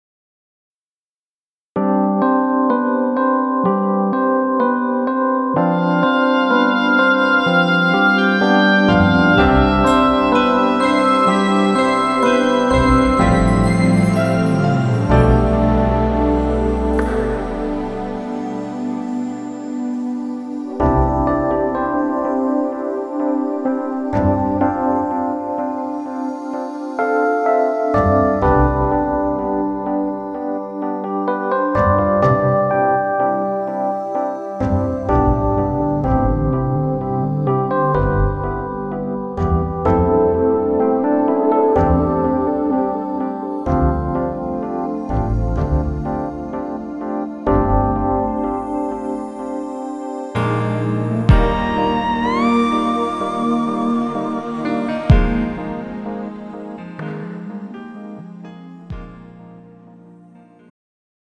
장르 축가 구분 Pro MR